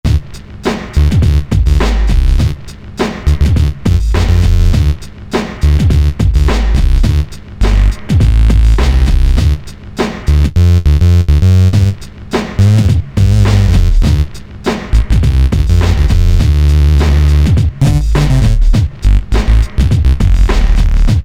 39 Loop-Bass5.mp3